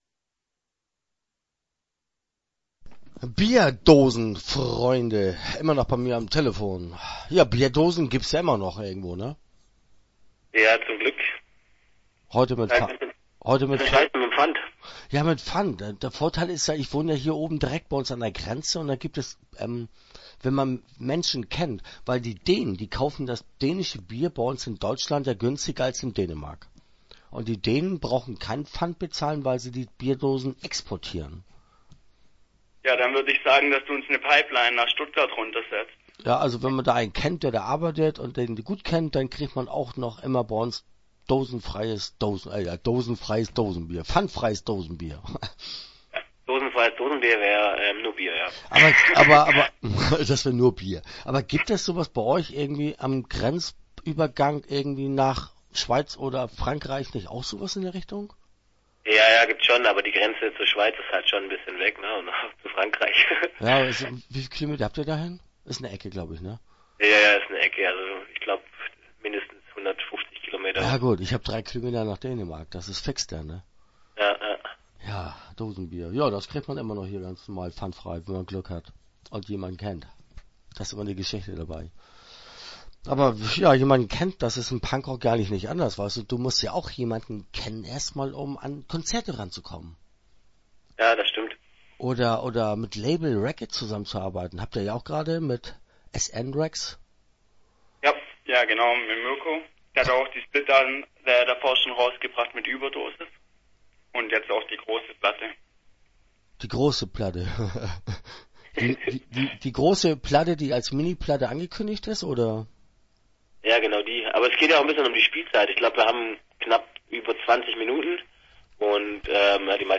Bierdosen Freunde - Interview Teil 1 (9:52)